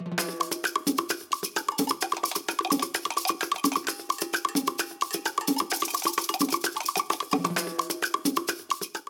Ripped from game